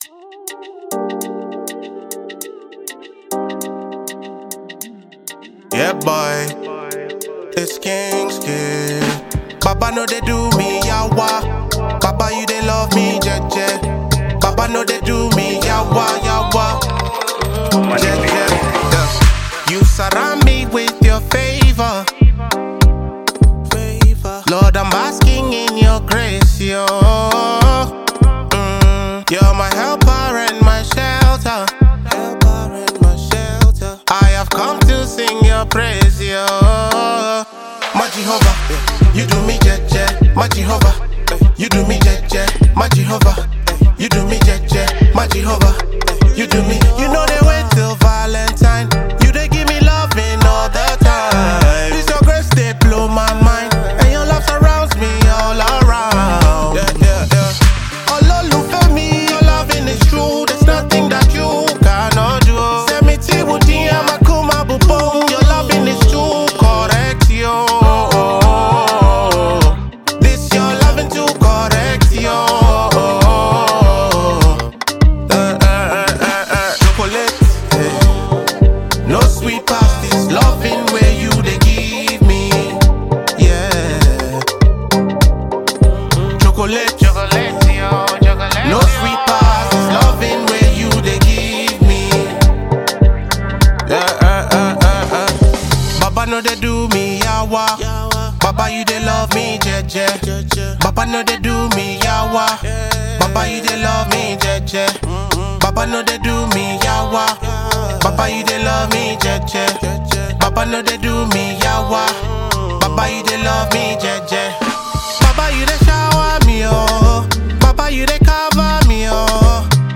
Urban Gospel
classic afrobeat vibe